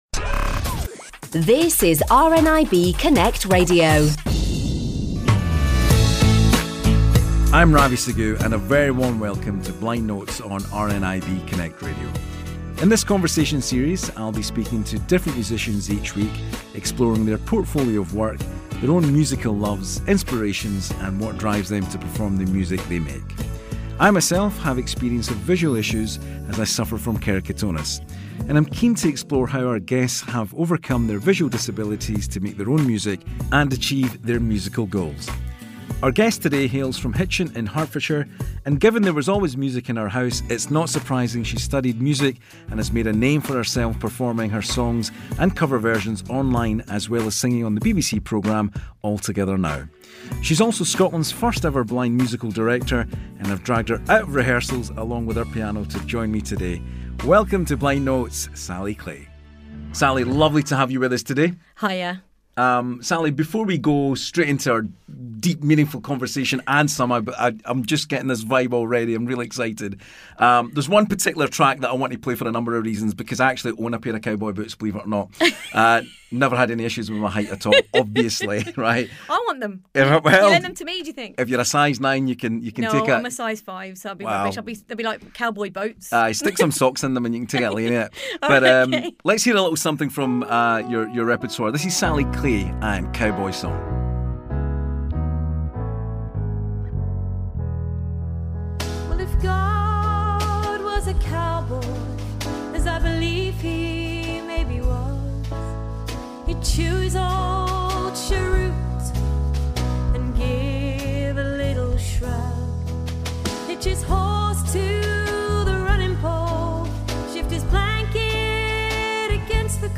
Blind Notes a seven-part conversation series of radio programmes featuring one-to-one interviews with musicians from around the world, all of whom are completely blind.